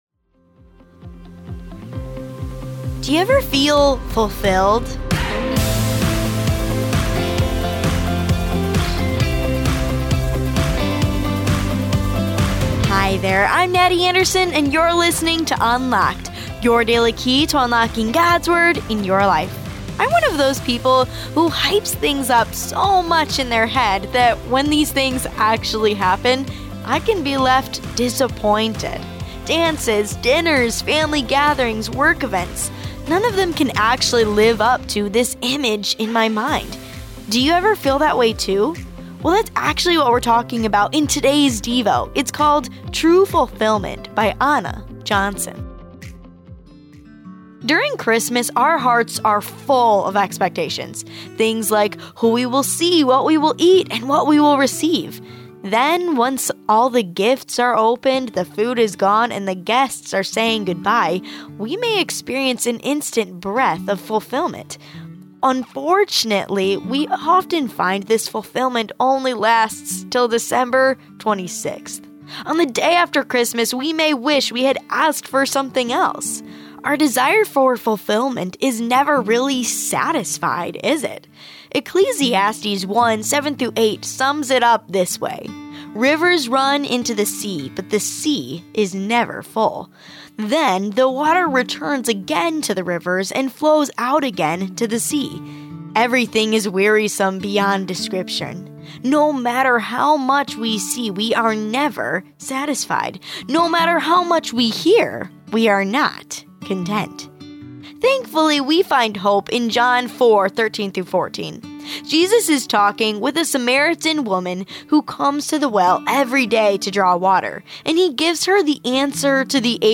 Religion Society Christianity Bedtime Stories Philosophy Keys For Kids Ministries 1 Corinthians